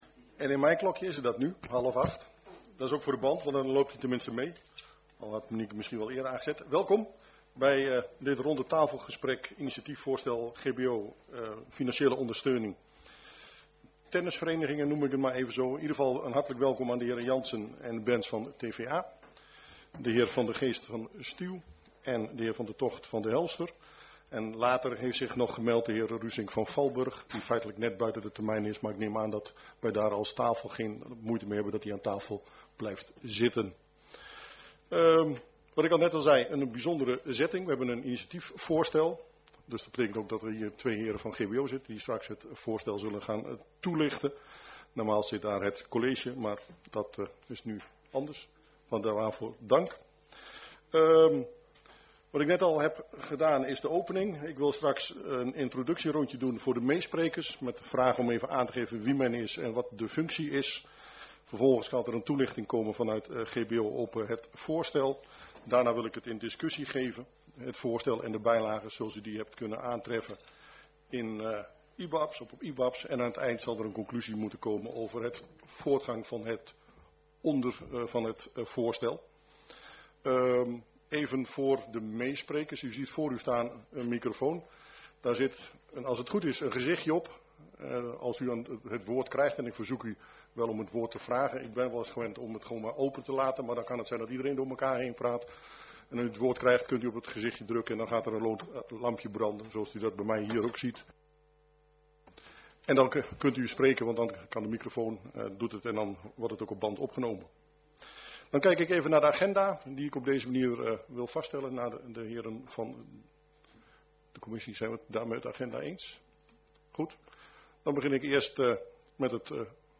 Agenda OverBetuwe - Voorronde commissiekamer dinsdag 6 februari 2018 19:30 - 20:45 - iBabs Publieksportaal
Locatie gemeentehuis Elst Voorzitter dhr. R. Beune Toelichting RTG Initiatiefvoorstel GBO over financiele ondersteuning t.b.v. het duurzaam renoveren van het sportcomplex Tennisvereniging Herveld-Andelst Agenda documenten 18-02-06 Opname 2.